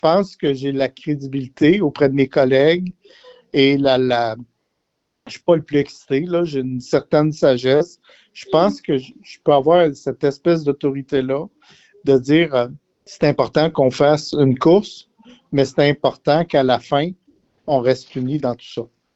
En entrevue, le ministre et député a mentionné qu’il souhaitait faire profiter de son expérience dans le monde politique.